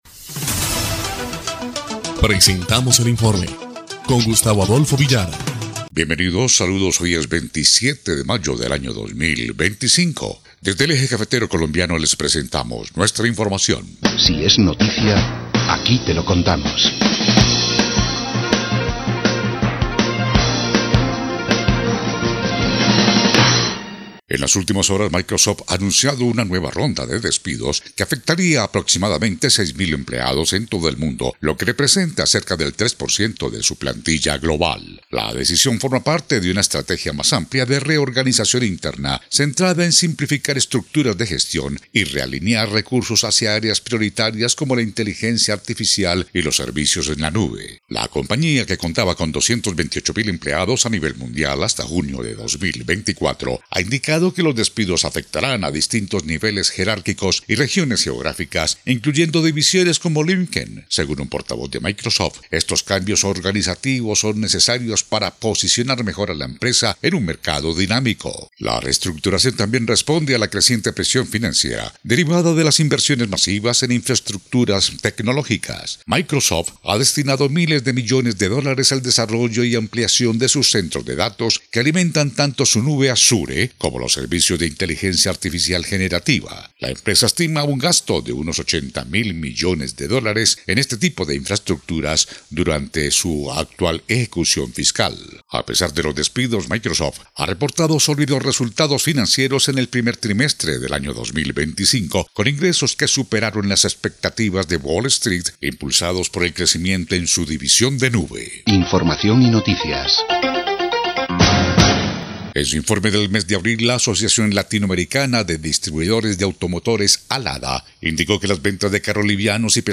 EL INFORME 2° Clip de Noticias del 27 de mayo de 2025